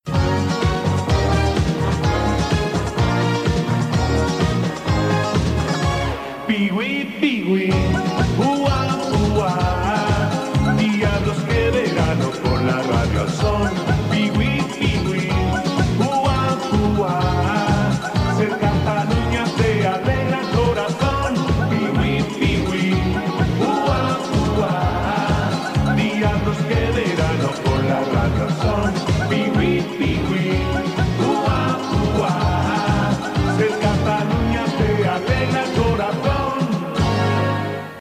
Cançó identificativa del programa